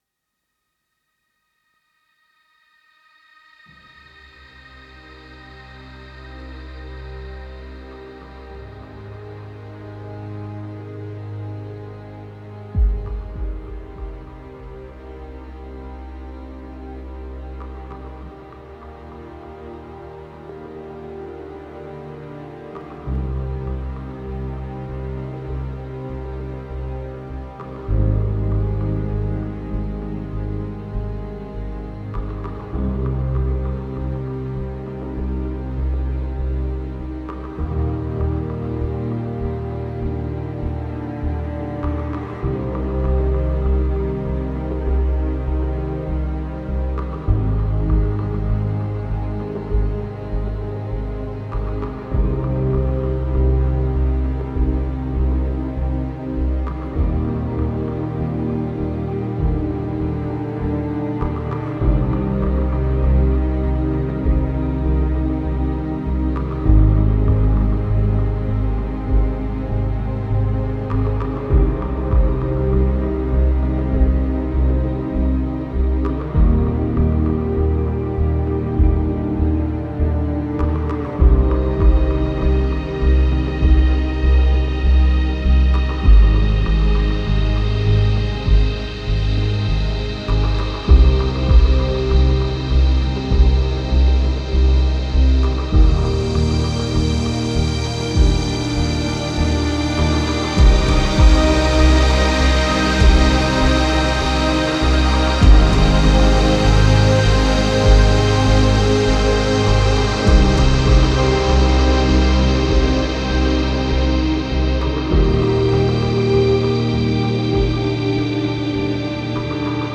BEST ORIGINAL SCORE